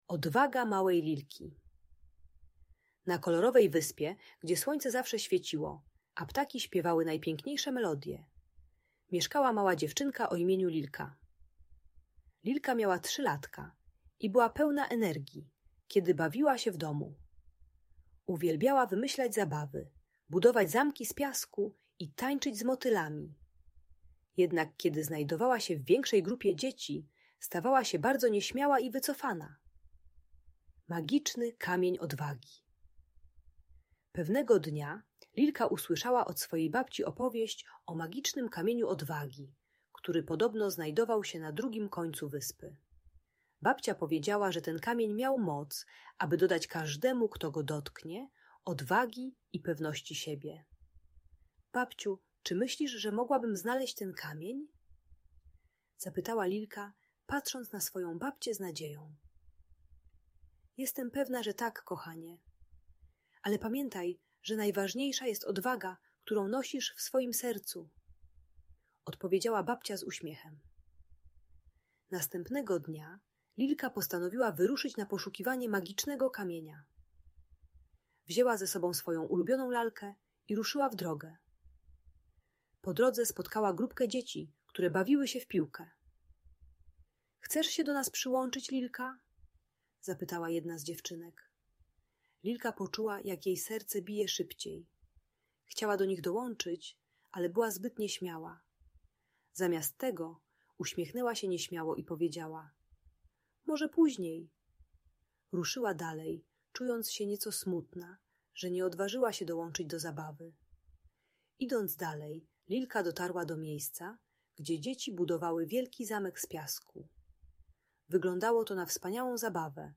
Historia o Lilce i Magicznym Kamieniu Odwagi - Audiobajka dla dzieci